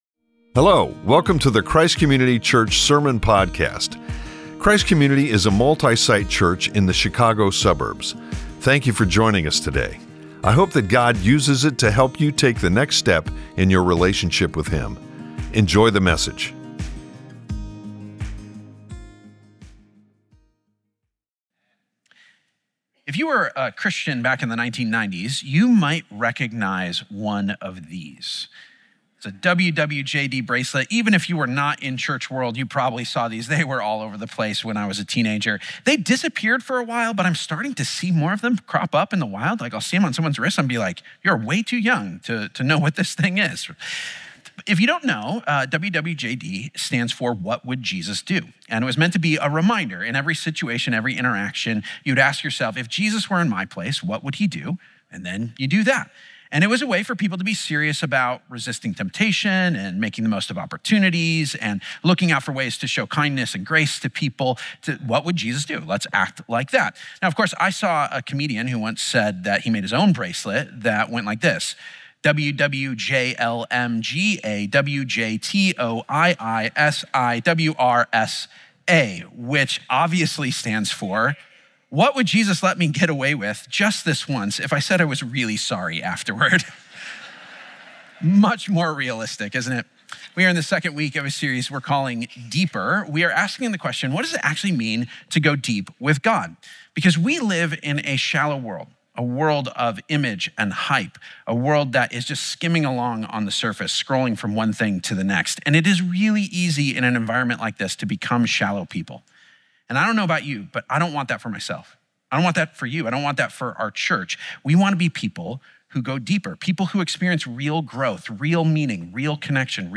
It's week two of the 'Deeper' sermon series. Going deeper means actually becoming like Jesus, not just putting up with the same old problems. It's about putting off the old self and putting on the new.